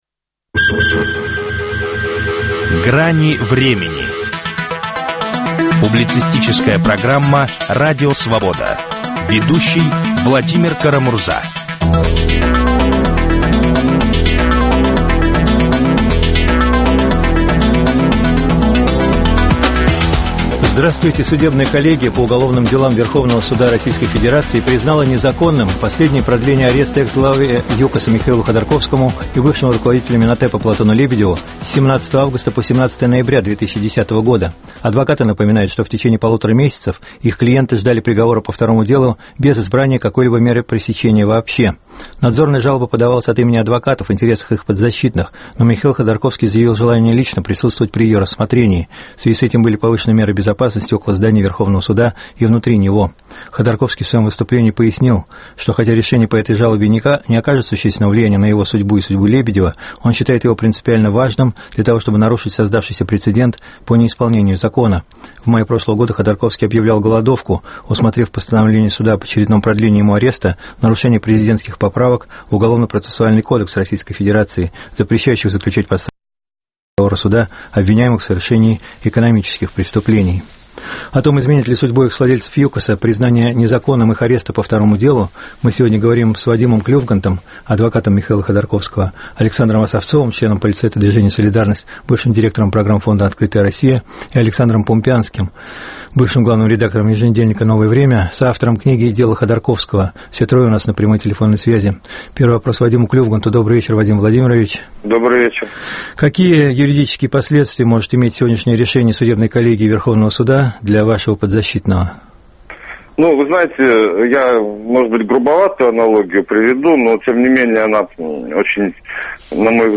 Изменит ли судьбу экс-владельцев ЮКОСа признание незаконным их ареста по второму делу? В эфире адвокат